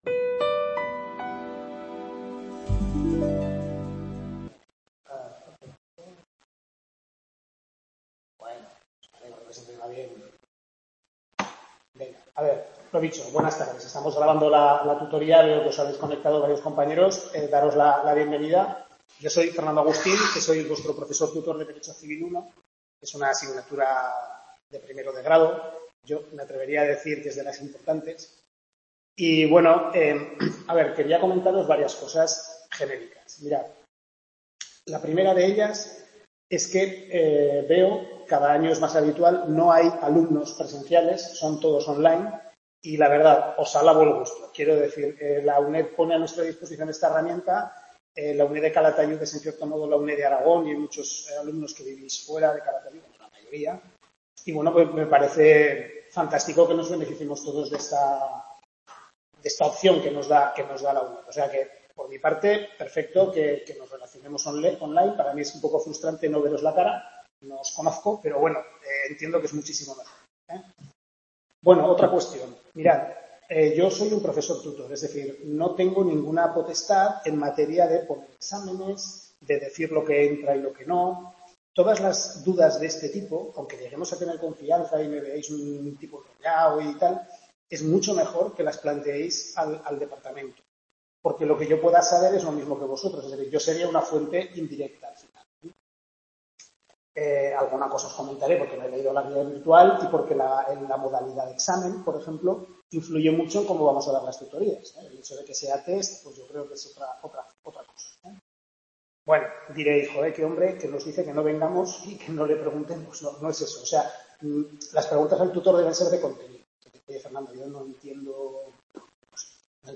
Tutoría 1/6 Civil I, Parte General, centro de Calatayud, curso 22-23